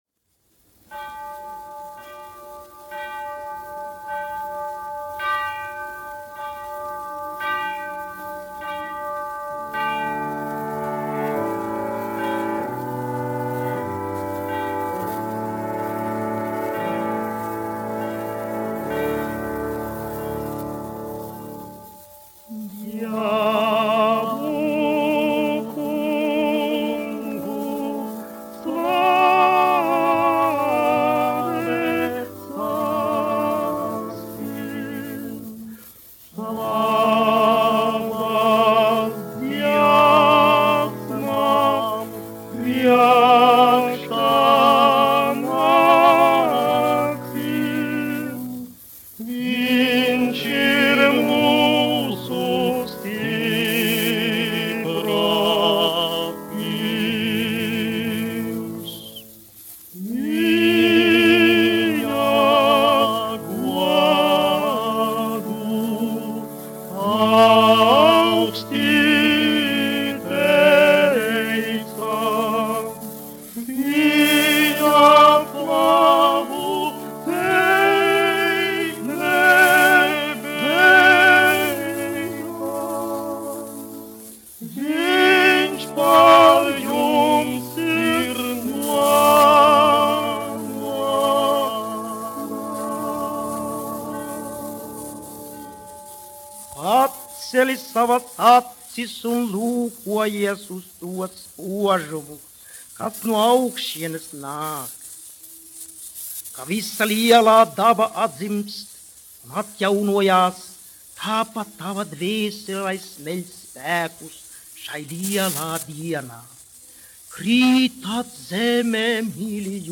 1 skpl. : analogs, 78 apgr/min, mono ; 25 cm
Garīgās dziesmas
Latvijas vēsturiskie šellaka skaņuplašu ieraksti (Kolekcija)